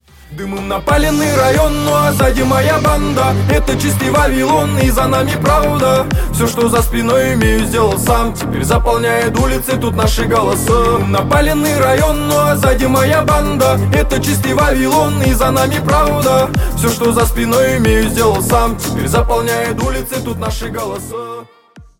Рэп и Хип Хоп # клубные